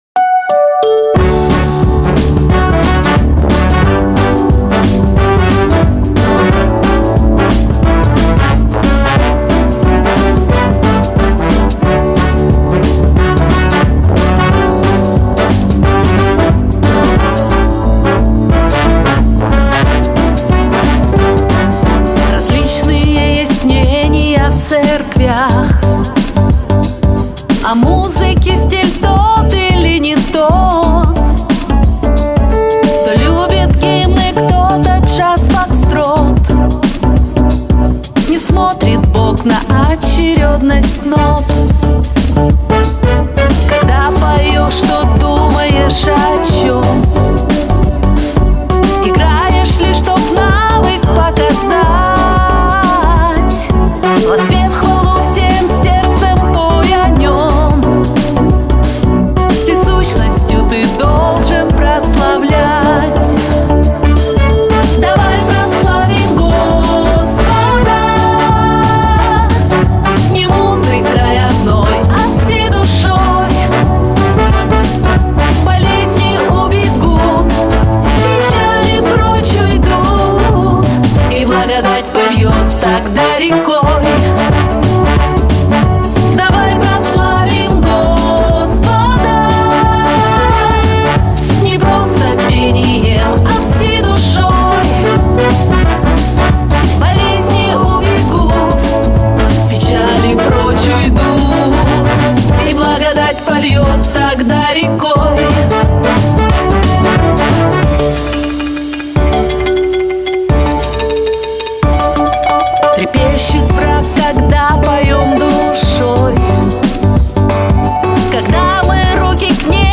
песня
248 просмотров 302 прослушивания 12 скачиваний BPM: 90